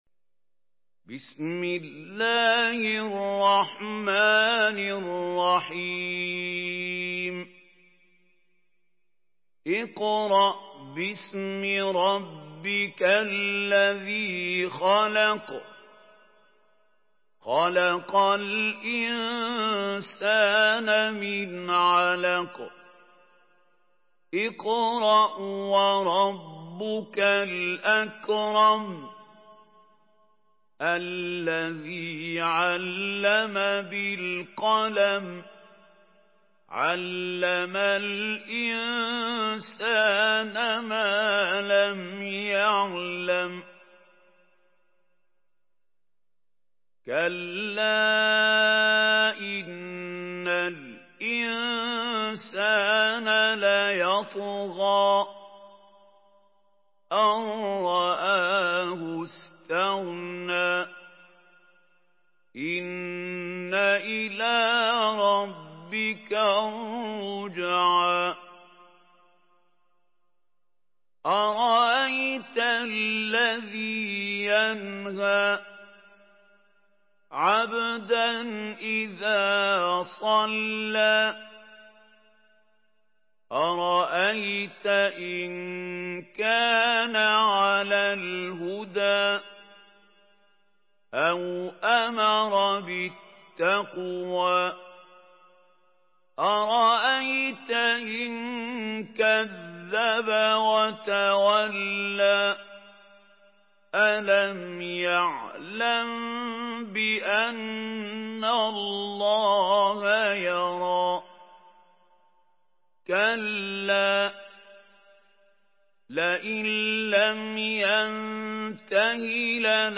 Surah Alak MP3 by Mahmoud Khalil Al-Hussary in Hafs An Asim narration.
Murattal Hafs An Asim